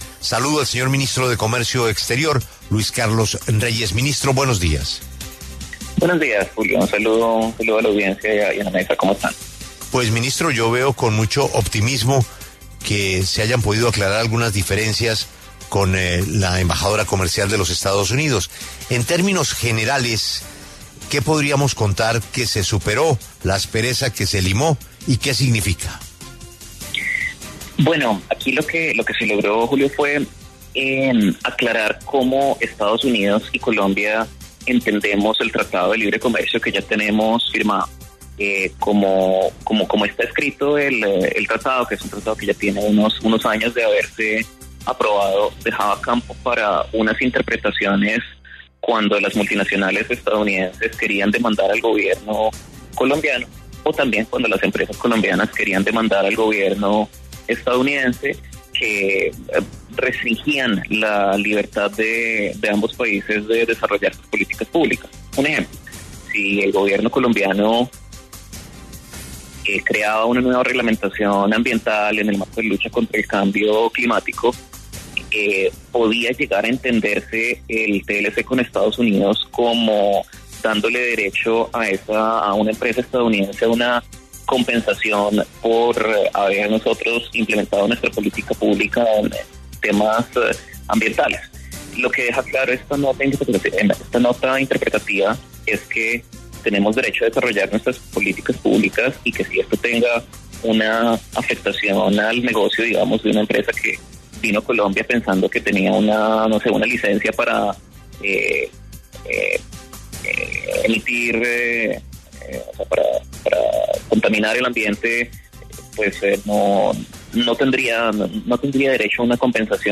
El ministro Luis Carlos Reyes explicó en La W los alcances de la nota aclaratoria sobre las disputas entre el Estado y los inversionistas en Estados Unidos. Afirmó que las razones de la inversión extranjera van más allá de si hay un tribunal de arbitramento internacional.